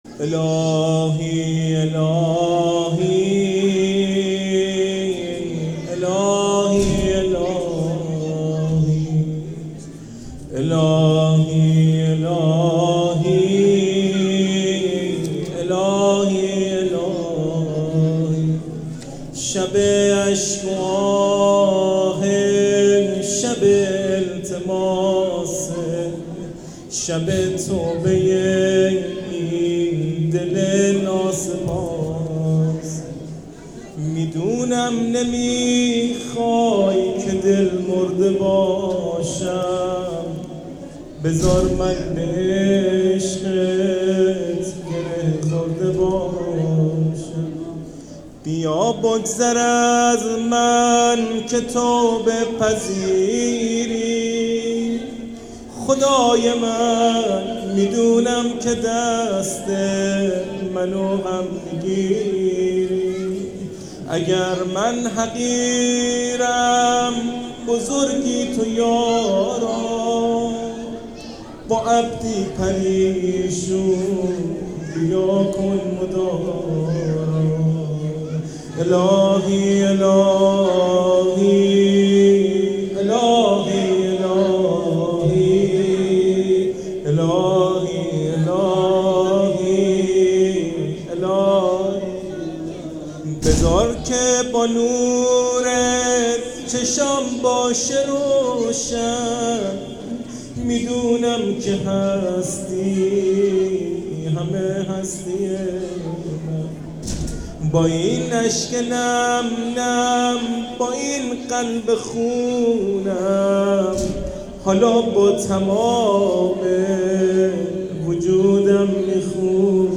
مناجات الهی الهی